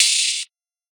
UHH_ElectroHatB_Hit-09.wav